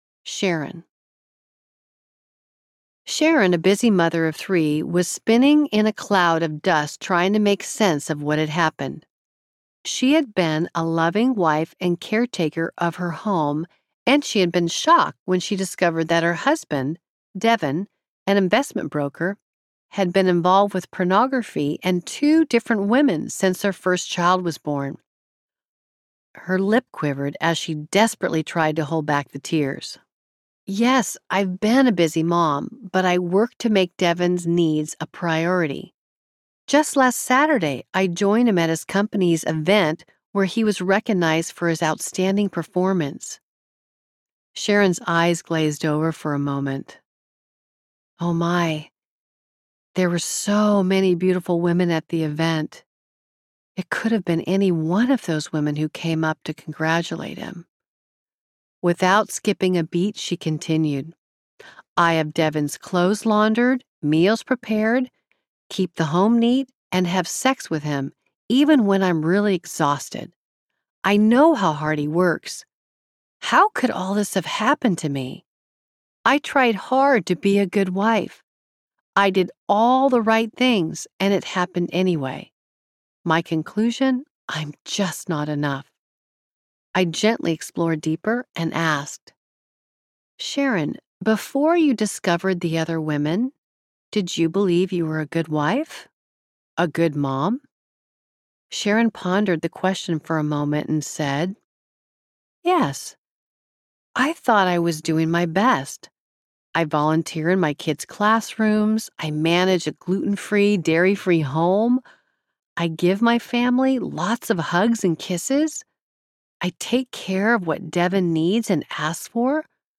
Intimate Deception Audiobook
Narrator
10.90 Hrs. – Unabridged